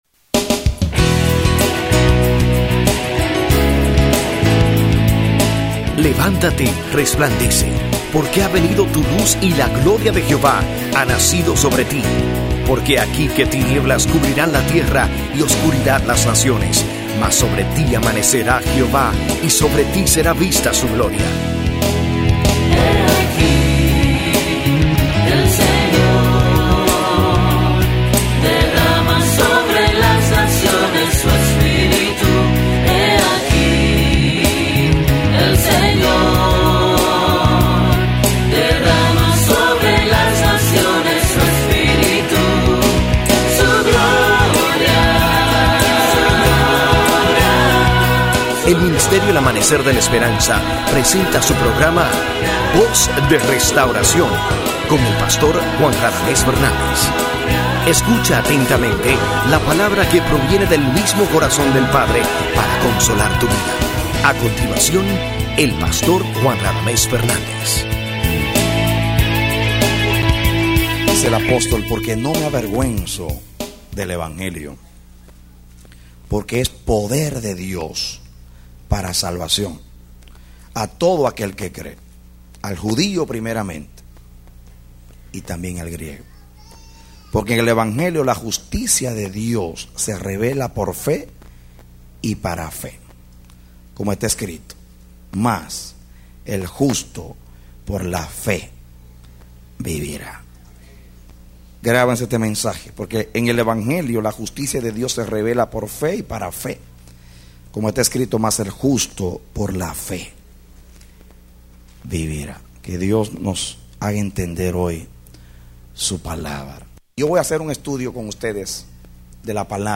A mensaje from the serie "Eres un Linaje Santo."